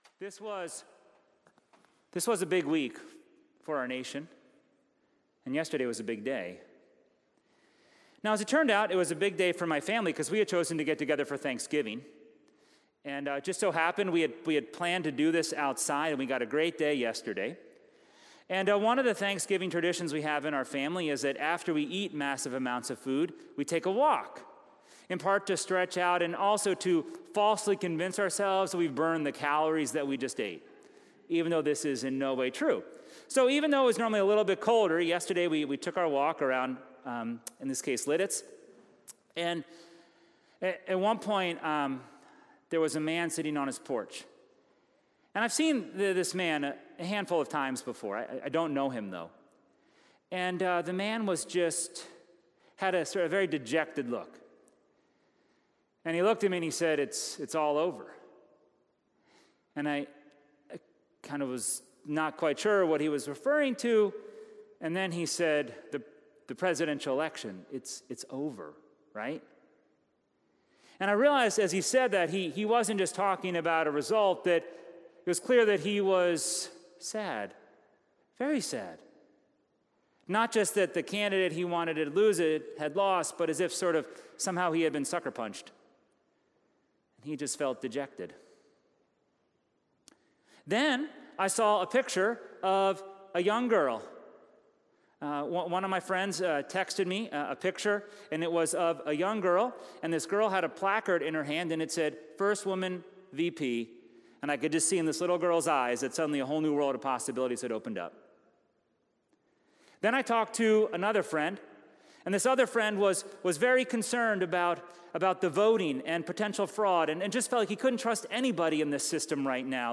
November 8, 2020 What do you preach to a congregation that is in a deeply purple town after such a divisive election and emotionally charged week?